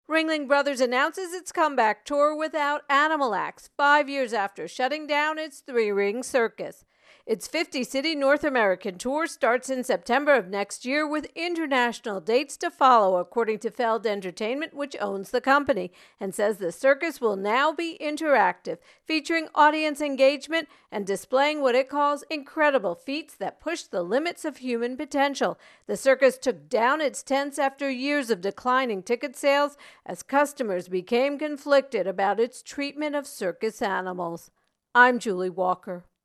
Return of the Circus intro and voicer